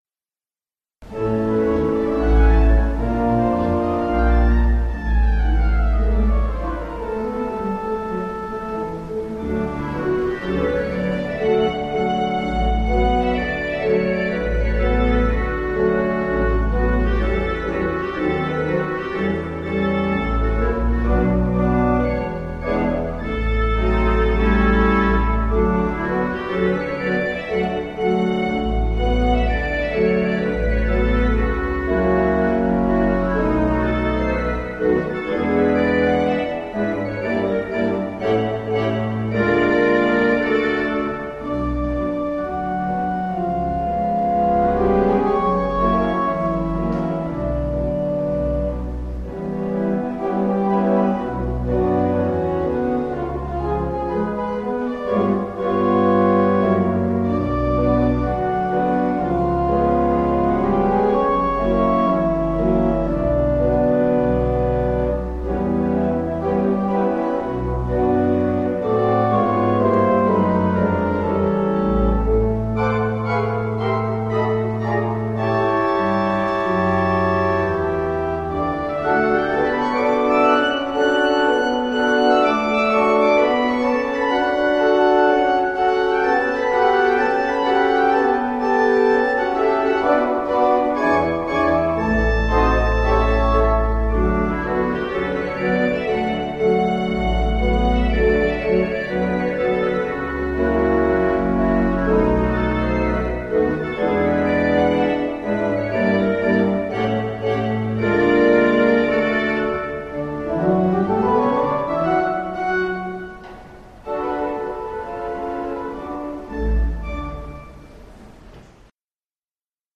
All other tracks are at St Anns Manchester.